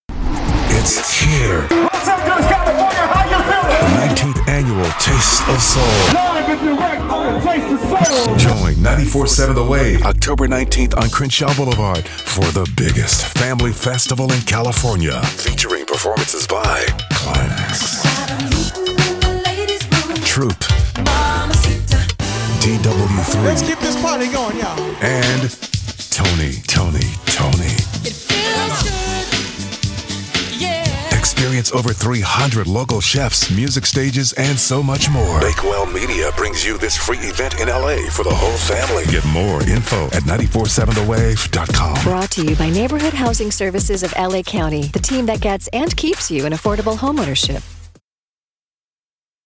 NHS Taste of Soul Radio Spot Promos
Taste-Of-Soul-2024-PROMO-Neighborhood-Housing-Services.mp3